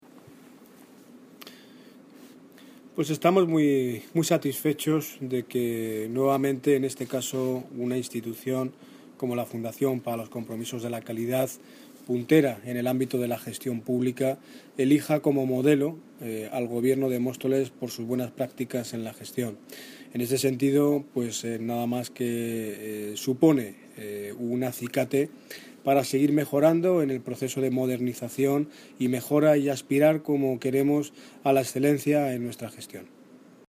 Audio - Daniel Ortiz (Alcalde de Móstoles) Sobre Calidad Gestión